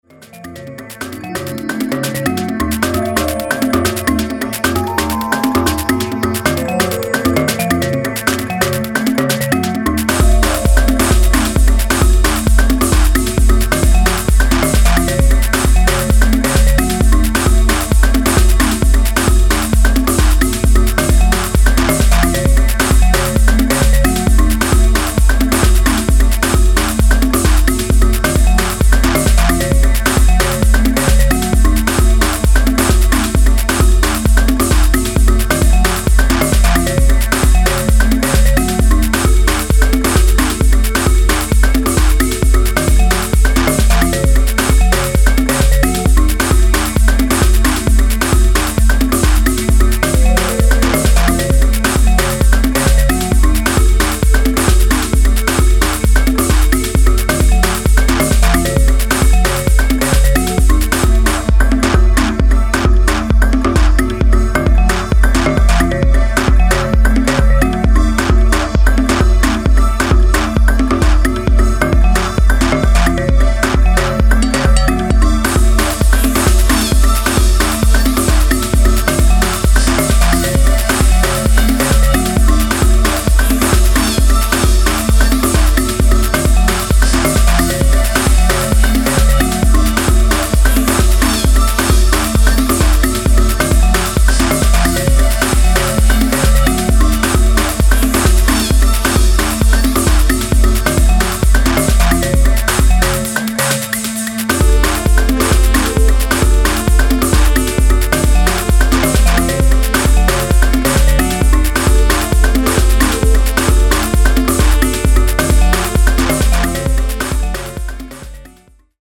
エスニックなムードの色彩感覚豊かなパーカッションがメロディアスなリフを弾き出す、かなり個性際立った